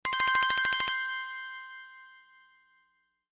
громкие
короткие
колокольчики
звонкие
3d звуки
Прикольная мелодия 3D